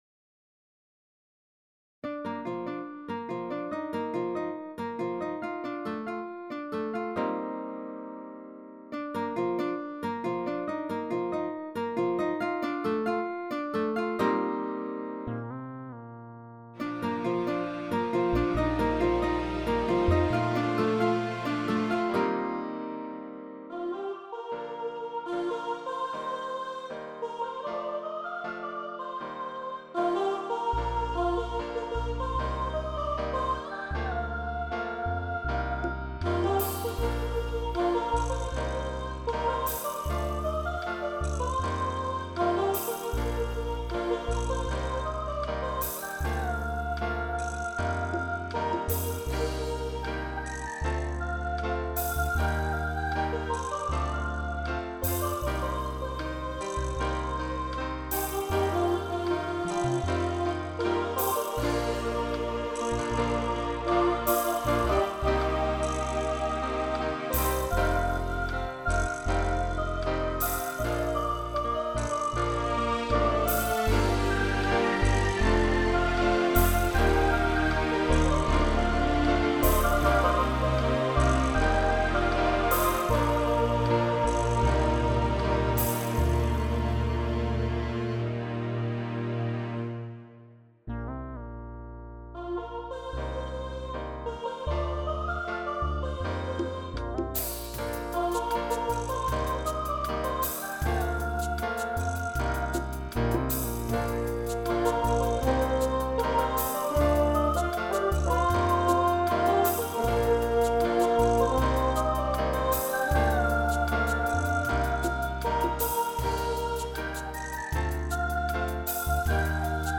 WAVE mixed with VST-effects.